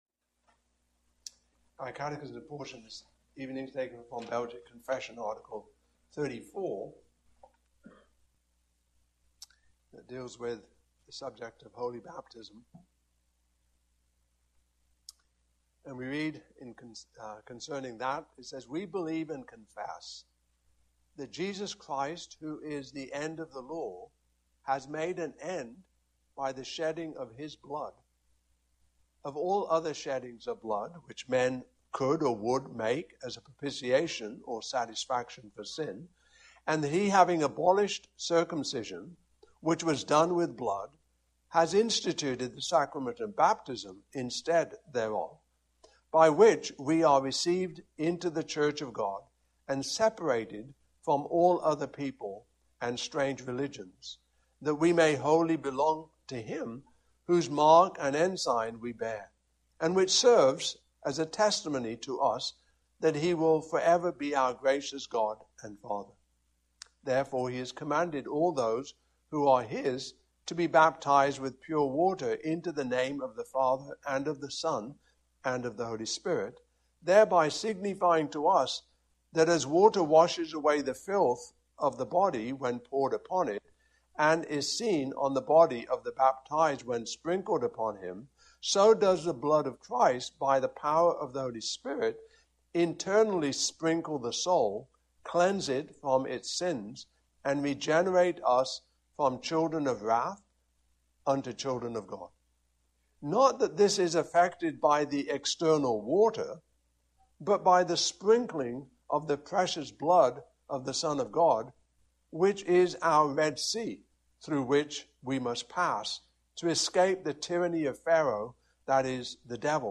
Passage: I Corinthians 7:12-16 Service Type: Evening Service